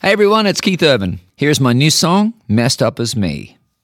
LINER Keith Urban (Messed Up As Me) 3